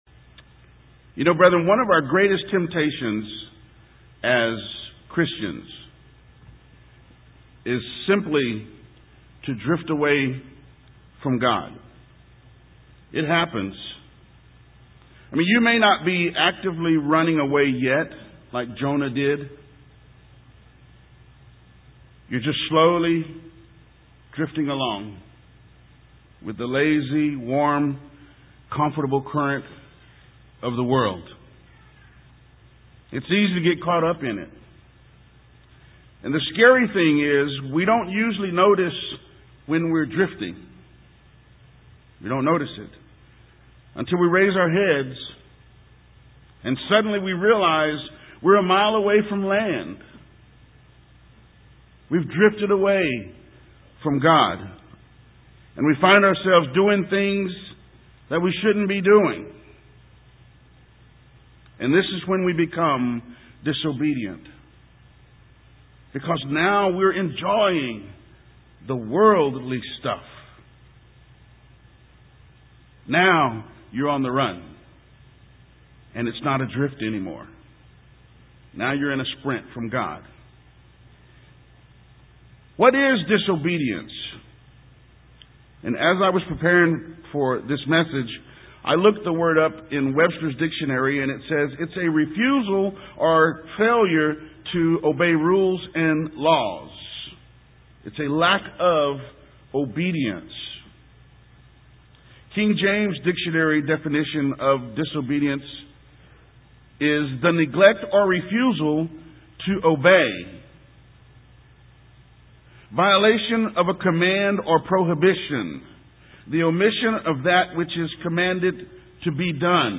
Sermons
Given in San Antonio, TX